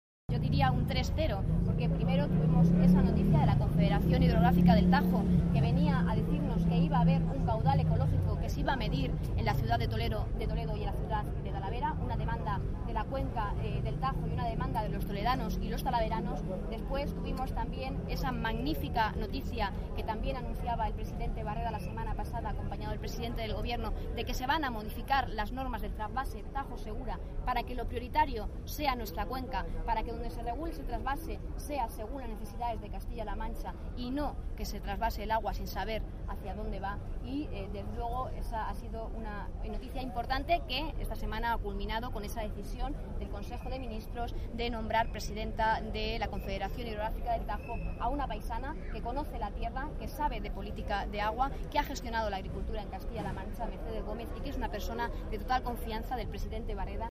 La portavoz del Gobierno de Castilla-La Mancha, Isabel Rodríguez, a preguntas de los periodistas durante la presentación en Puertollano (Ciudad Real) de la campaña itinerante del PSCM-PSOE ‘Sí a Castilla-La Mancha’, aseguró que en materia de agua la política del Ejecutivo castellano-manchego “ha marcado un 3-0” al trasvase Tajo-Segura.
Cortes de audio de la rueda de prensa